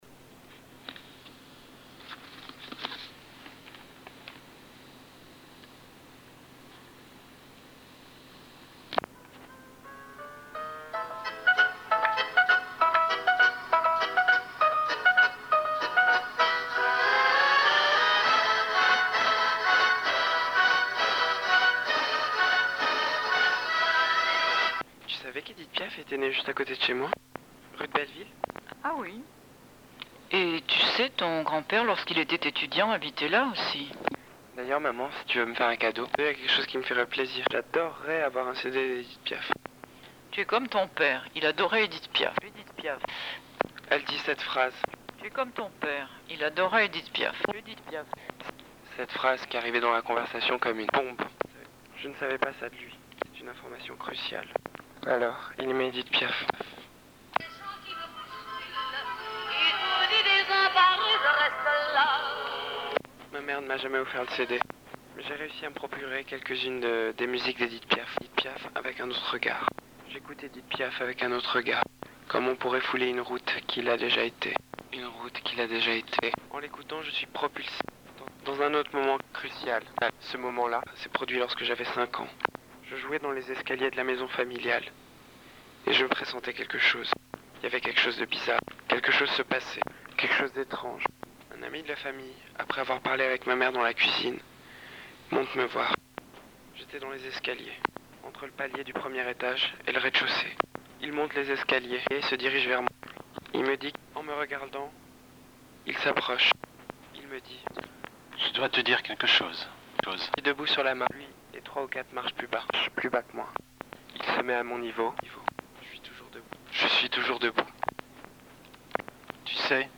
Comme dans la plupart de tes pièces, tu donnes une instruction d’écoute, ici : « écouter cette pièce sonore, entre deux marches, dans des escaliers ».
Pour ce faire, il y a le travail d’écriture puis d’interprétation de mes textes, car je m’enregistre en train de les jouer.